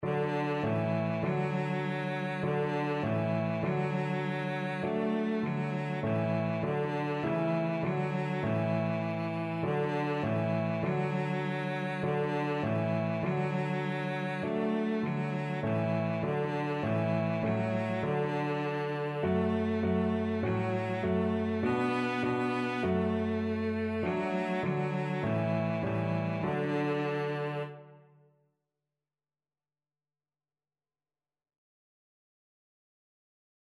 Cello
4/4 (View more 4/4 Music)
Simply
D4-B4
D major (Sounding Pitch) (View more D major Music for Cello )
Traditional (View more Traditional Cello Music)